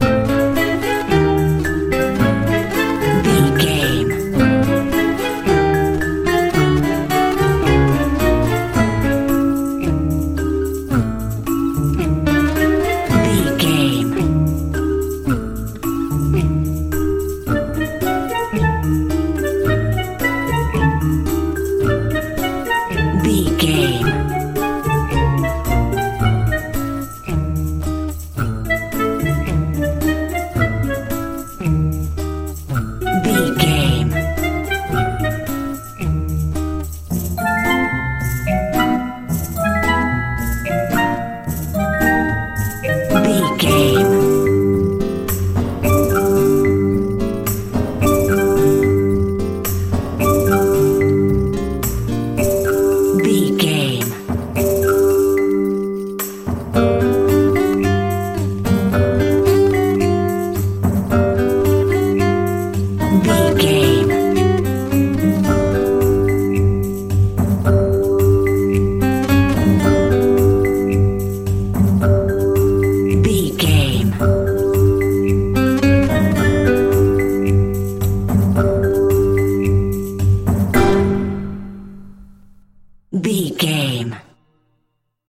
Uplifting
Diminished
flute
oboe
strings
orchestra
cello
double bass
percussion